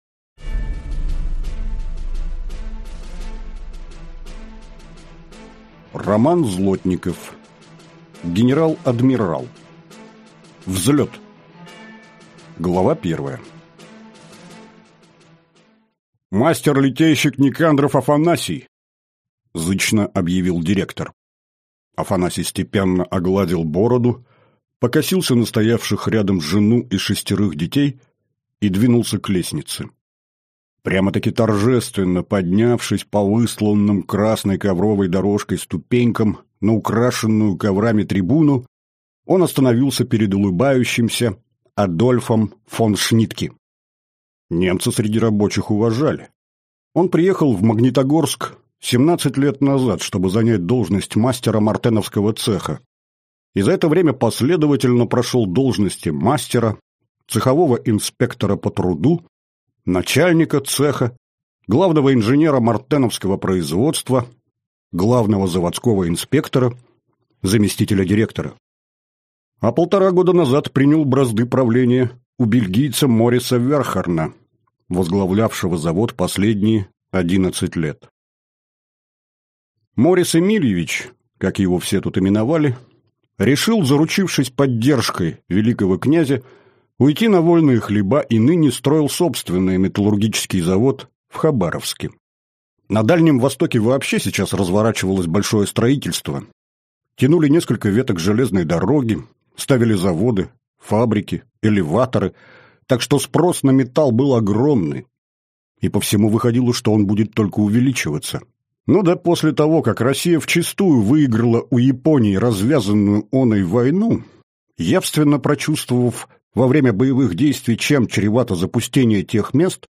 Аудиокнига Взлет - купить, скачать и слушать онлайн | КнигоПоиск